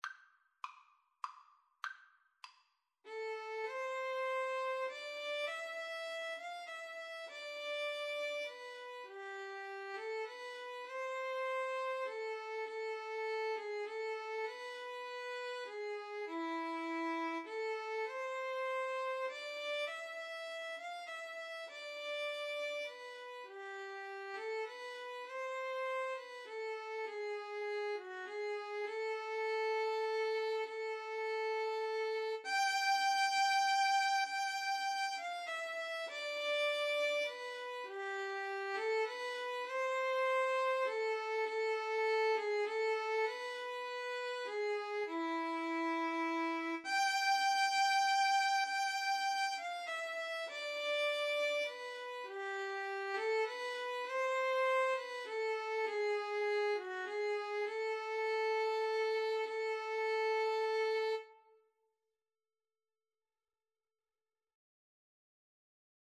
Free Sheet music for Violin-Guitar Duet
A minor (Sounding Pitch) (View more A minor Music for Violin-Guitar Duet )
3/4 (View more 3/4 Music)
Traditional (View more Traditional Violin-Guitar Duet Music)